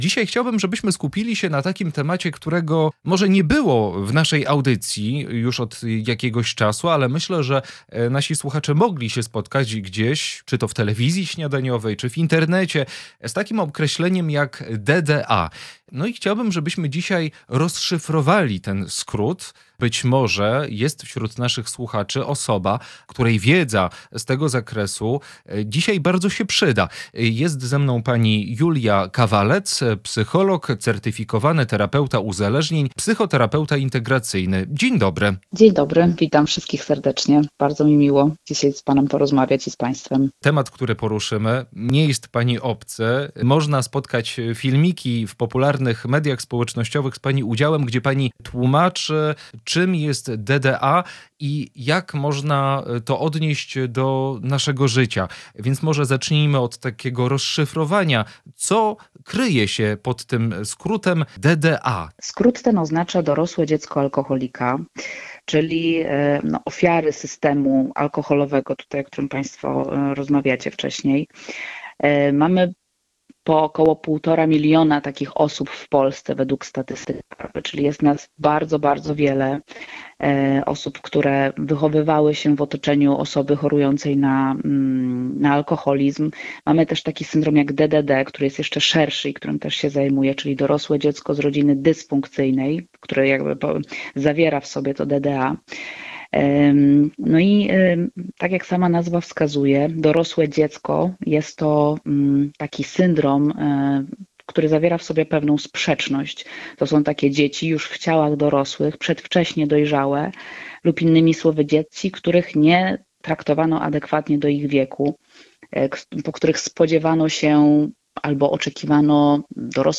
Poniżej pełna rozmowa: